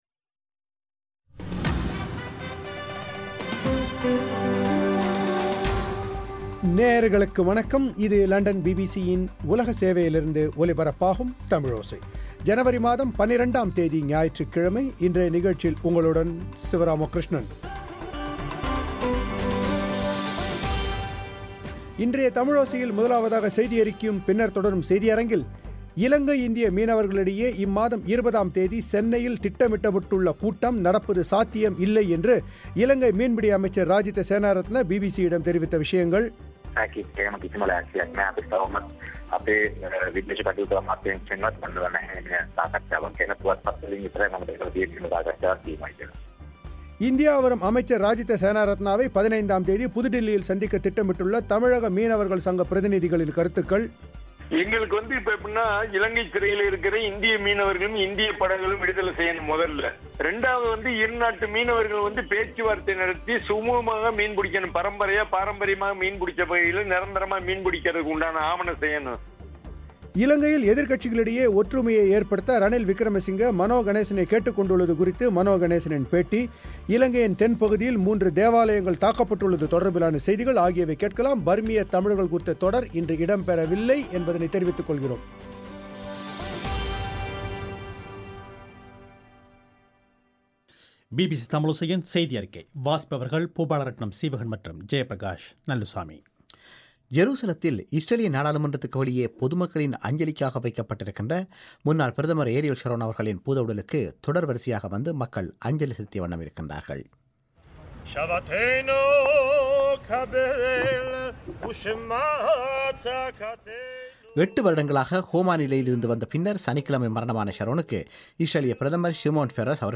எதிர்கட்சிகளிடையே ஒற்றுமையை ஏற்படுத்த ரணில் விக்ரமசிங்க, மனோ கணேசனை கேட்டுக் கொண்டுள்ளது குறித்து மனோ கணேசனின் பேட்டி.